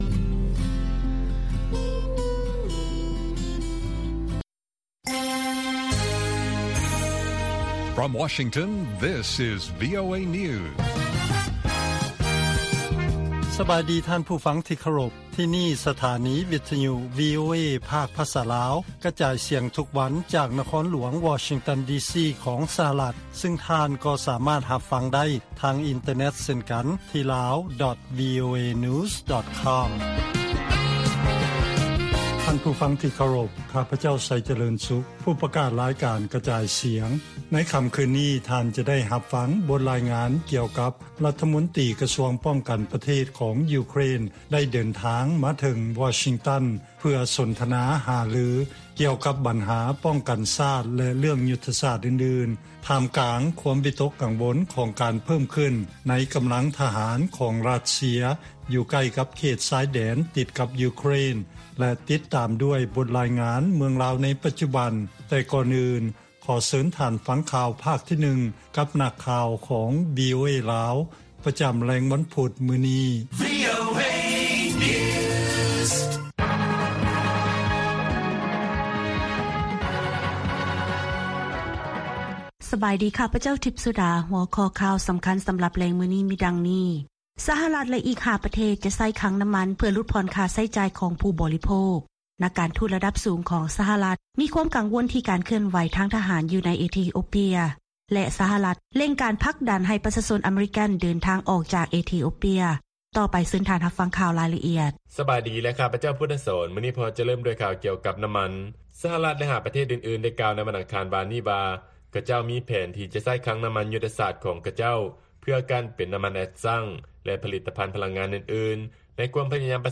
ລາຍການກະຈາຍສຽງຂອງວີໂອເອ ລາວ: ສະຫະລັດ ແລະ ອີກ 5 ປະເທດ ຈະໃຊ້ຄັງນໍ້າມັນ ເພື່ອຫຼຸດຜ່ອນຄ່າໃຊ້ຈ່າຍ ຂອງຜູ້ບໍລິໂພກ